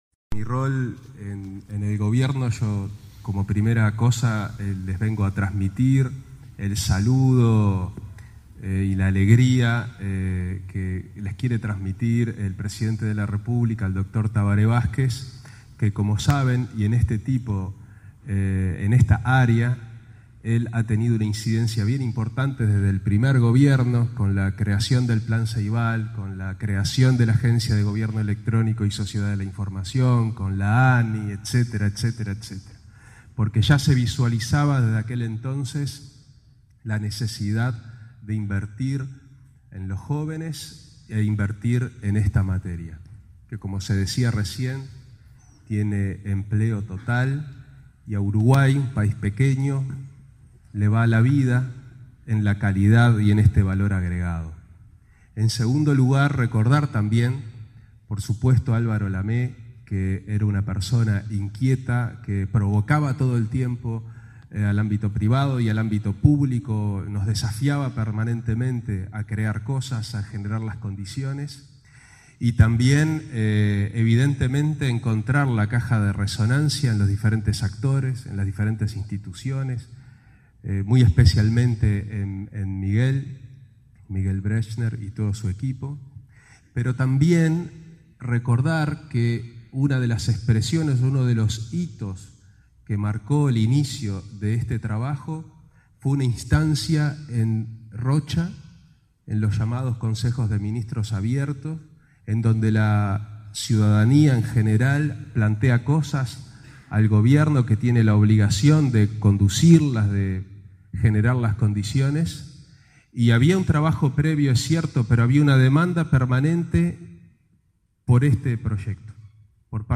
Roballo participó este viernes de la ceremonia de graduación de 700 estudiantes de Jóvenes a Programar.